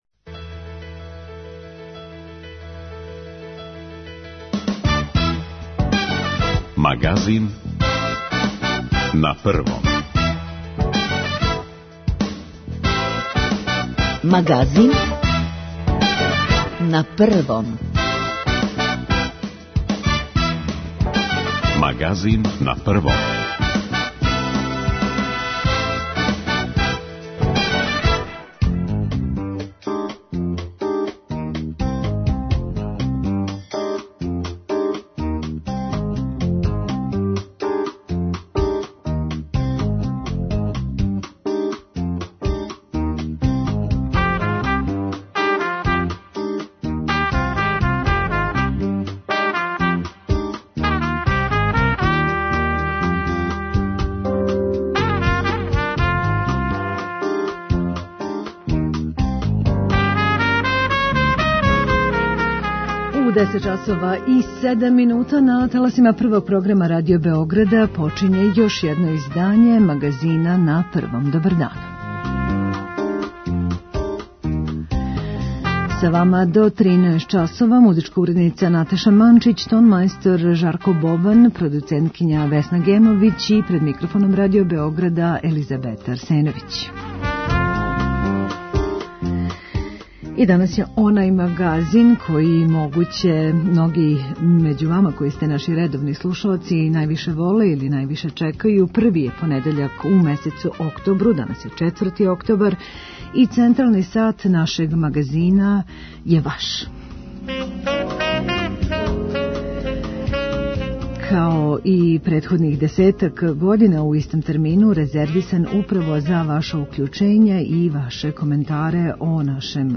Већ више од десет година првог понедељка у месецу, централни термин преподневног Магазина на Првом програму Радио Београда резервисан је за укључења слушалаца и за коментаре о нашем раду - похвале, покуде и сугестије.